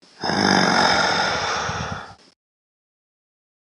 Horror
Zombie Breath Out Single is a free horror sound effect available for download in MP3 format.
yt_HsD96I5AAjY_zombie_breath_out_single.mp3